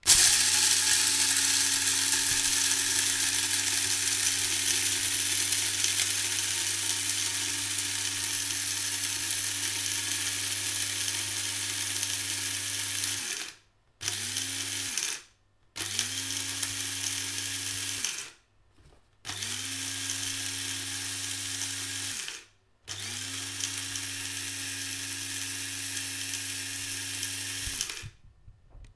small cofee grinder.wav
Small coffee grinder, gridning some fresh coffee beans in my kitchen, recorded with a Tascam DR 40.
Channels Stereo
small_cofee_grinder_nam.mp3